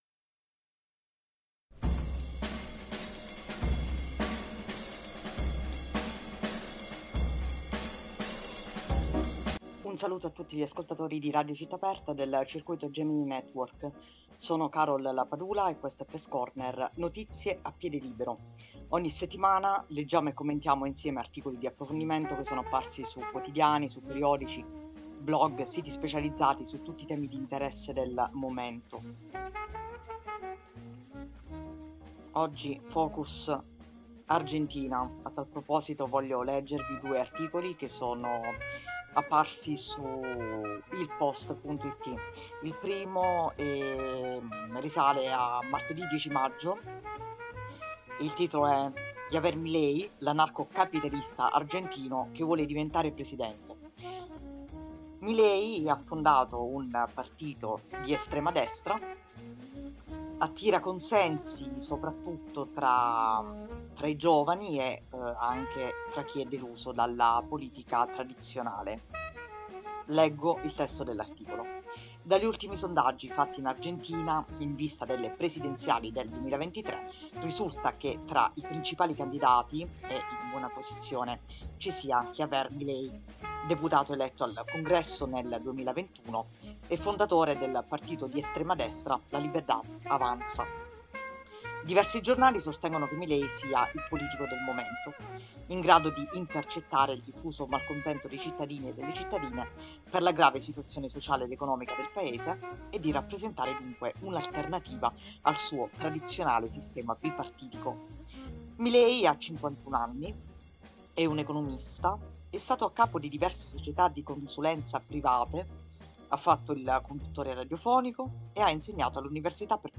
Leggiamo e commentiamo insieme articoli di approfondimento apparsi su quotidiani, periodici, blog, siti specializzati su tutti i temi di interesse del momento.